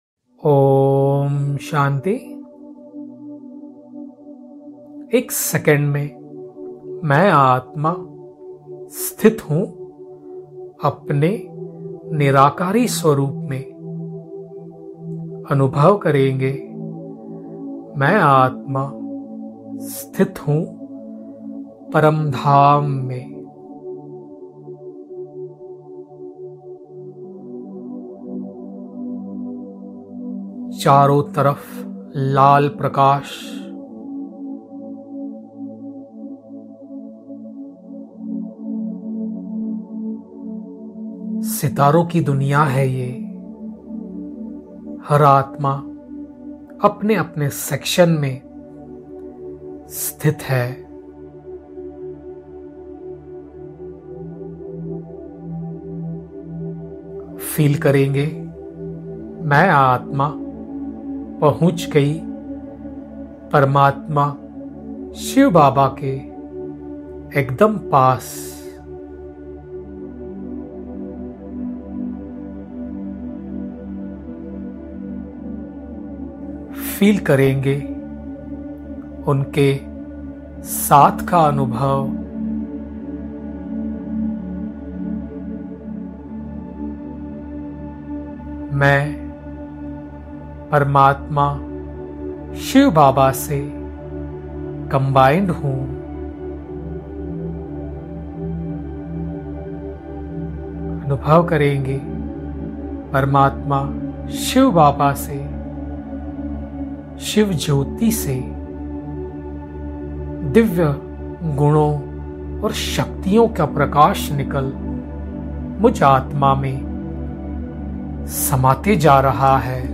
430. Amritvela Meditation Commentary.mp3